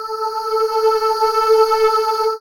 Index of /90_sSampleCDs/Techno_Trance_Essentials/CHOIR
64_09_voicesyn-A.wav